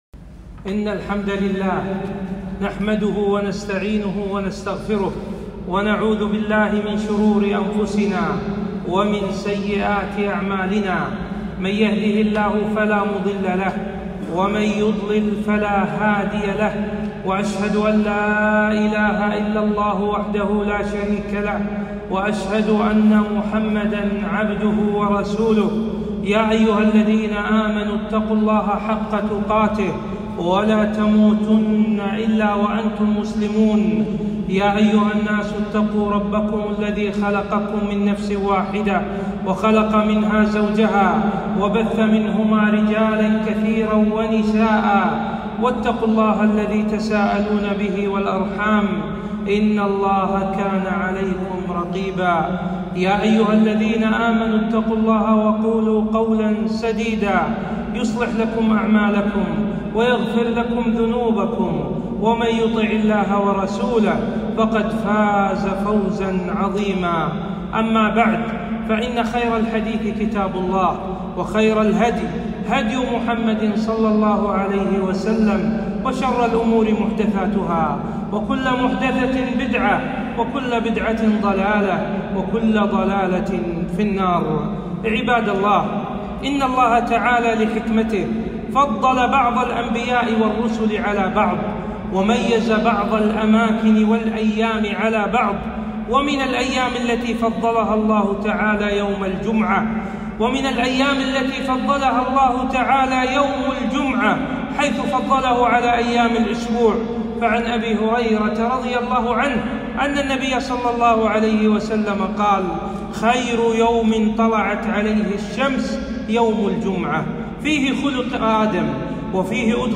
خطبة - فضائل وأحكام